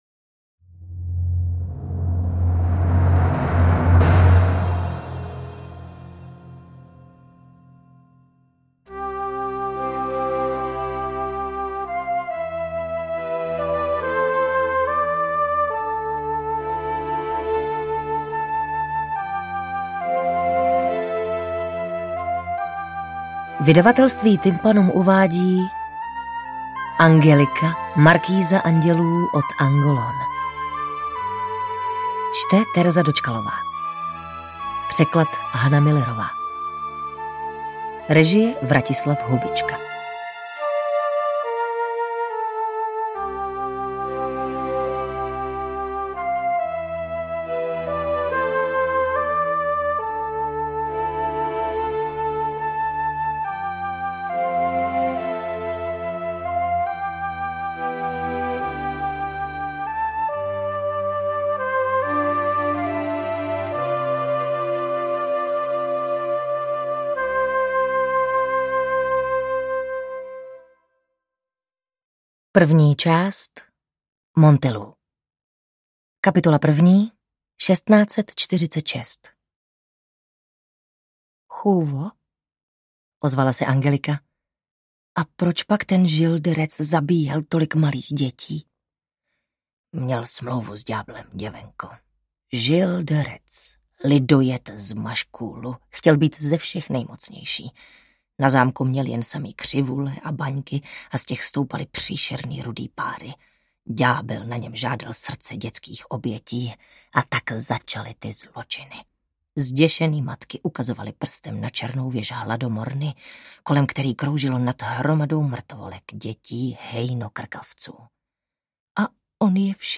Interpret:  Tereza Dočkalová
AudioKniha ke stažení, 27 x mp3, délka 9 hod. 25 min., velikost 519,5 MB, česky